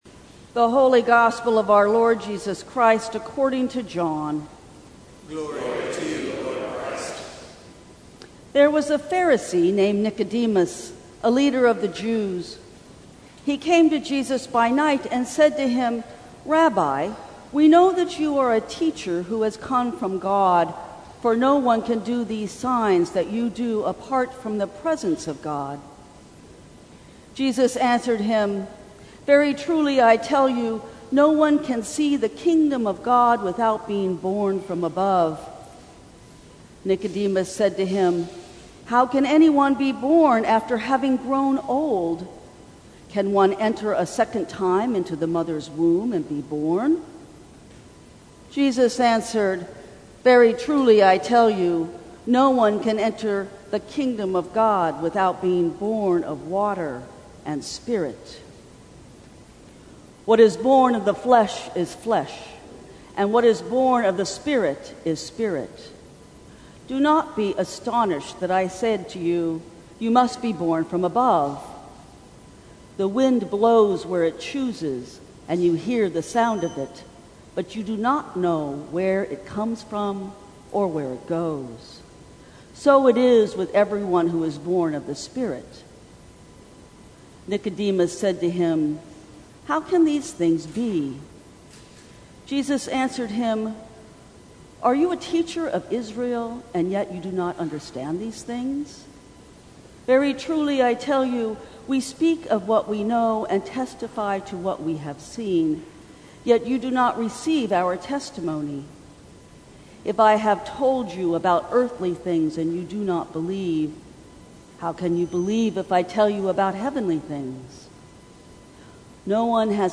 Sermons from St. Cross Episcopal Church Second Sunday in Lent Mar 10 2020 | 00:15:24 1x 00:00 / 00:15:24 Subscribe Share Apple Podcasts Spotify Overcast RSS Feed Share Link Embed